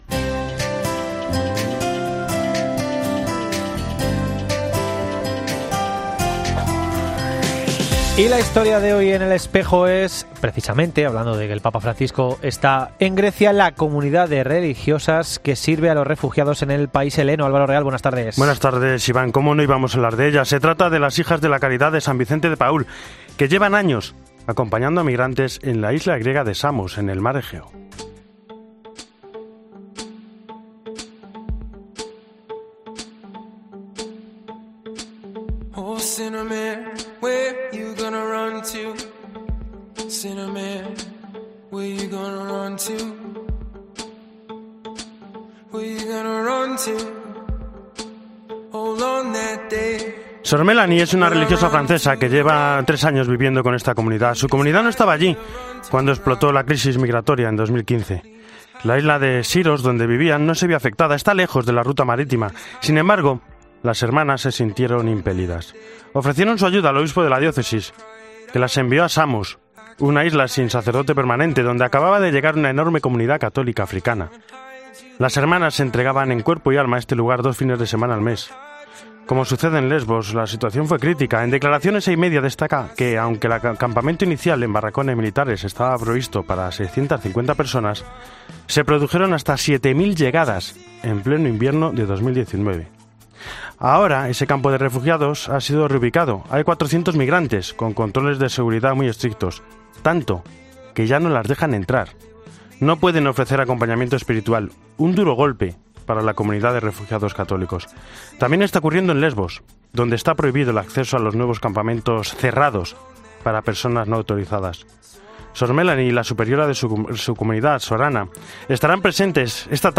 Reportaje
Iglesia en Roma y el mundo.